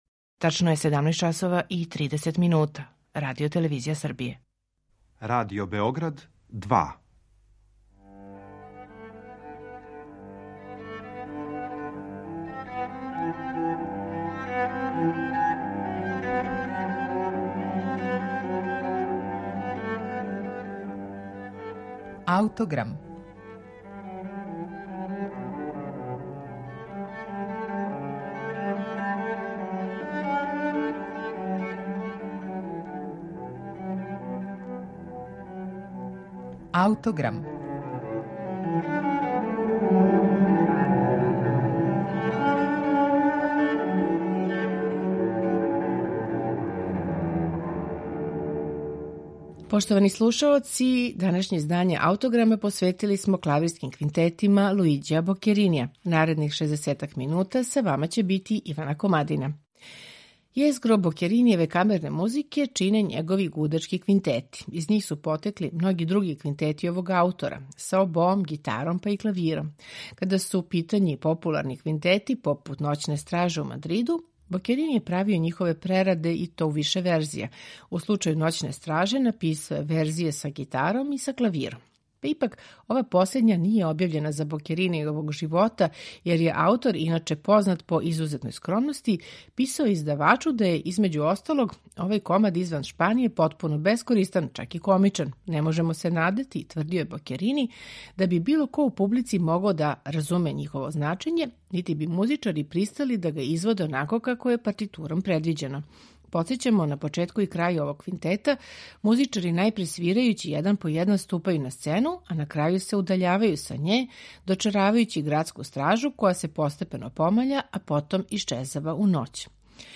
У данашњем Аутограму квинтете из ових Бокеринијевих збирки слушамо у интерпретацији ансамбла Claveire, чији чланови свирају на оригиналним инструментима Бокеринијевог доба.
Вероватно је управо он био тај који је од Бокеринија 1797. године наручио шест квинтета за клавир и гудаче, у то време релативно нову форму.